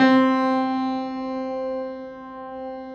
53g-pno10-C2.wav